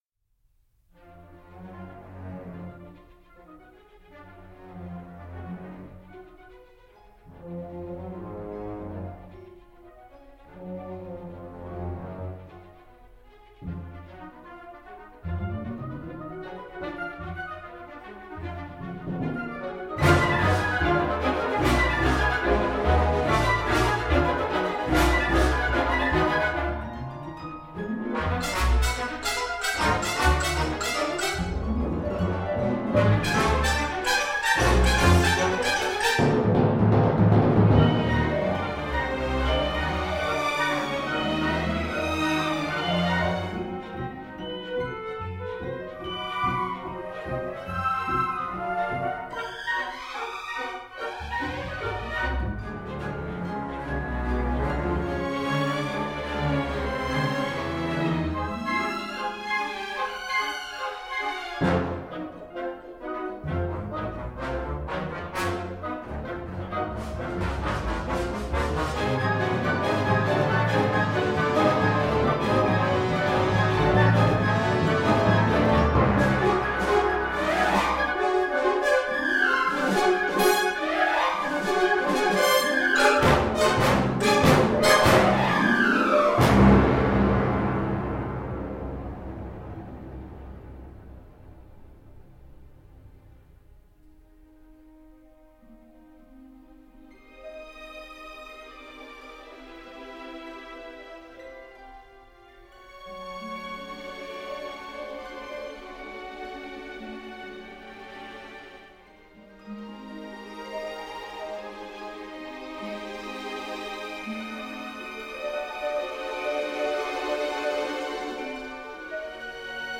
KONTAKT标准音色，直接入库
- 24 bit / 48 kHz 的采样质量
- Studio Orchestra
- Ondes Martenot